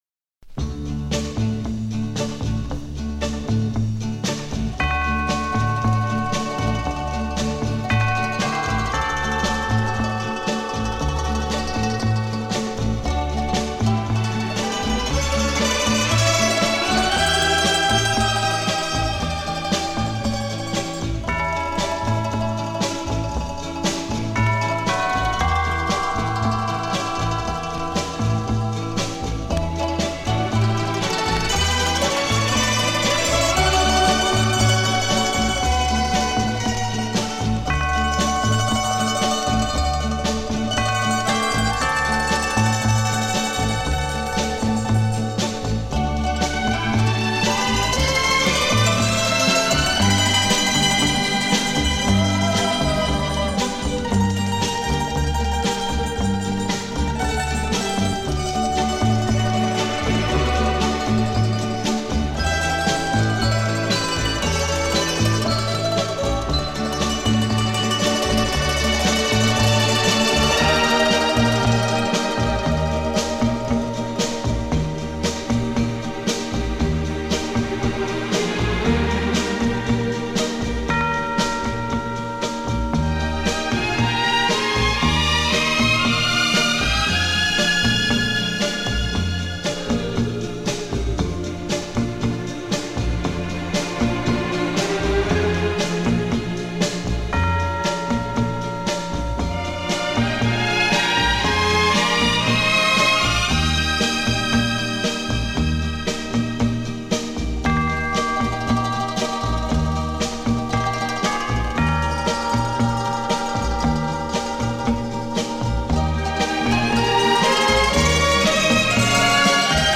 Genre:Easy Listening,Instrumental